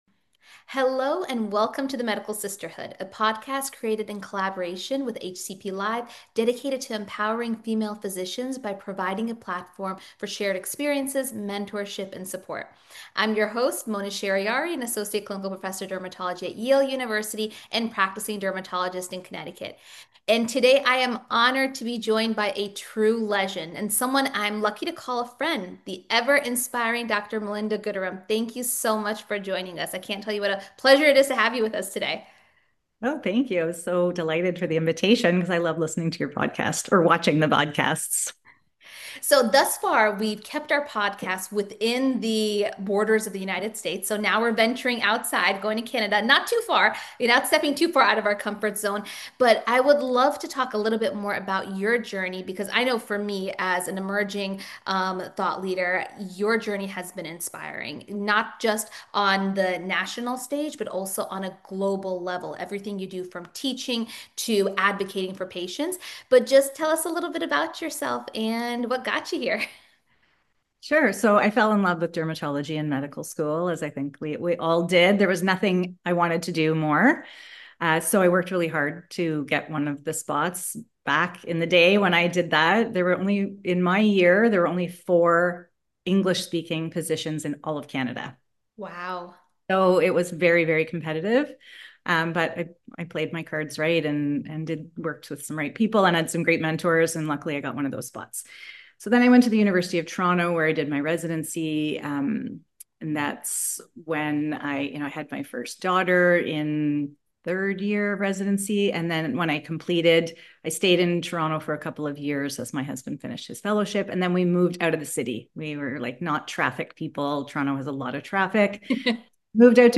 The interview features a candid and inspiring conversation on building a high-impact career in dermatology while navigating the complexities of leadership, motherhood, and self-doubt.